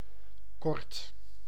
Ääntäminen
US : IPA : [ʃɔrt] RP : IPA : /ʃɔːt/